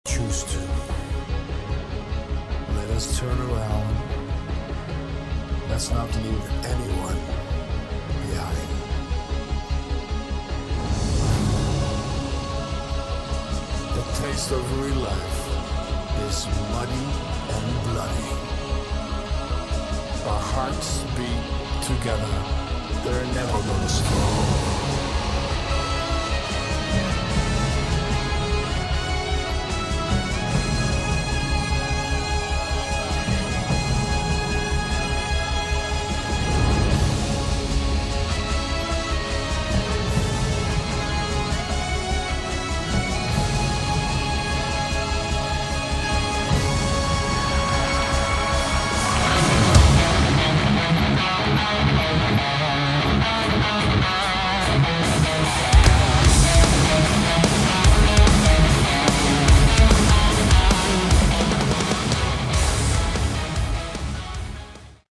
Category: Melodic Rock
lead vocals, guitars
keyboards, backing vocals
recorded at Vaasa, Elisa Stadium in Finland in summer 2022.